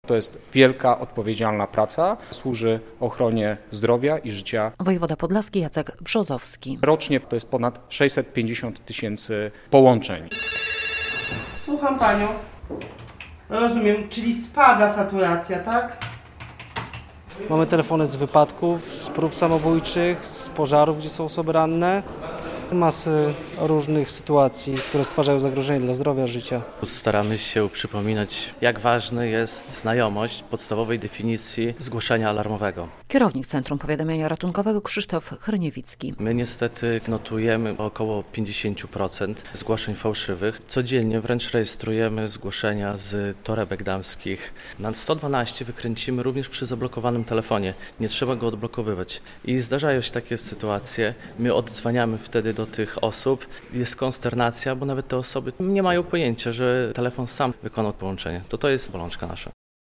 Z okazji Europejskiego Dnia Numeru Alarmowego 112 zorganizowano spotkanie w Centrum Powiadamiania Ratunkowego w Białymstoku - relacja